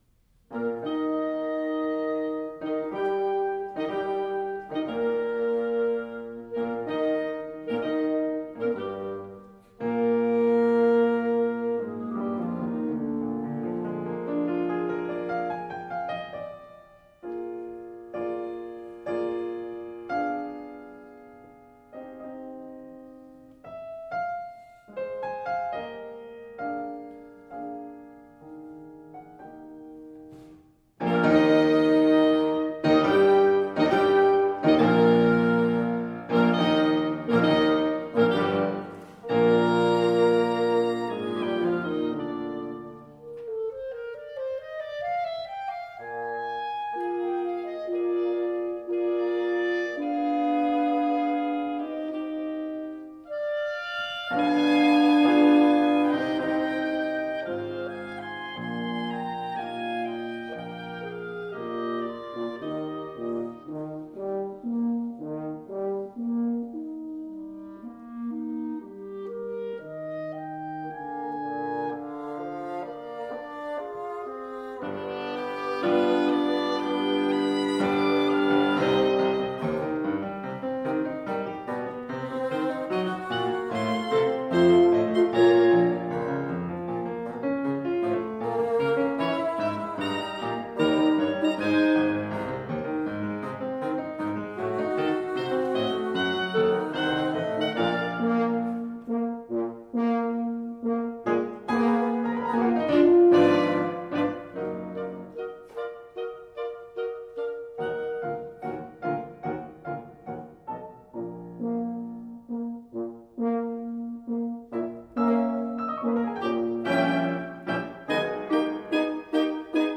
Oboe Clarinet French Horn Bassoon
Style: Classical
Audio: Boston - Isabella Stewart Gardner Museum